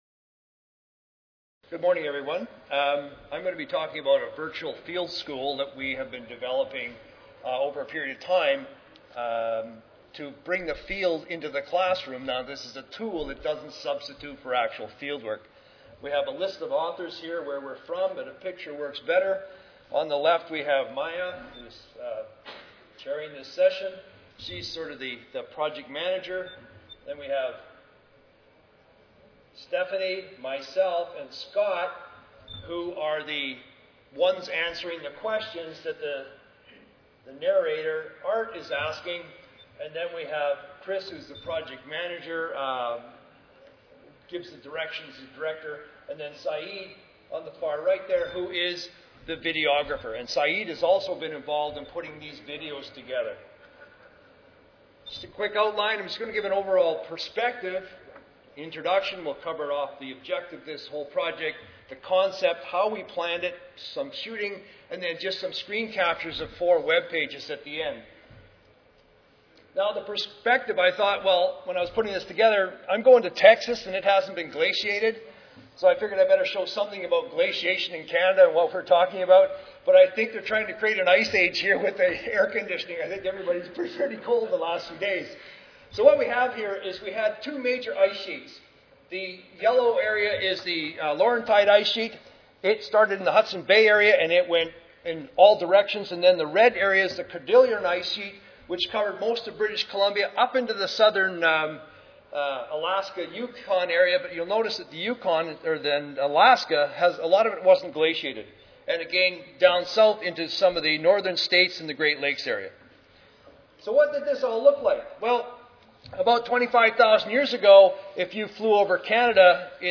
S05 Pedology Session: Innovations In Soil Science Education: I (ASA, CSSA and SSSA Annual Meetings (San Antonio, TX - Oct. 16-19, 2011))
Recorded Presentation